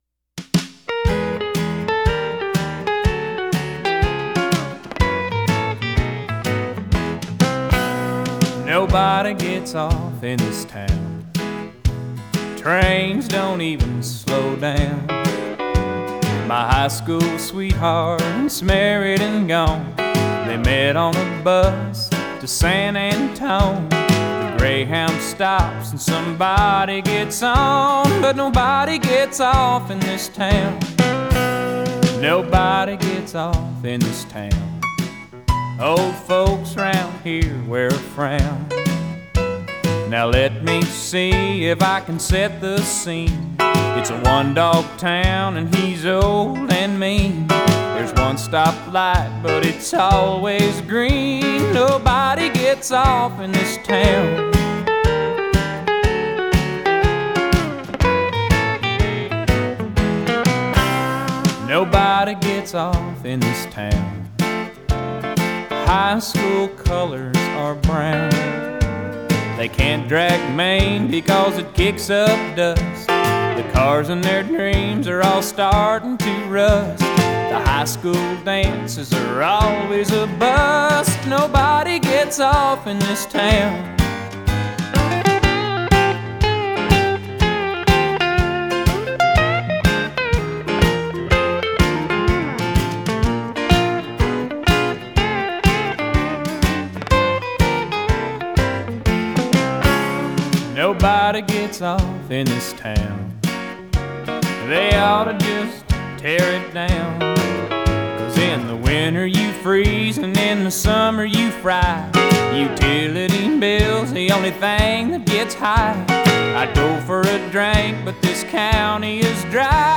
Это самый успешный исполнитель кантри-музыки в 90-х в США.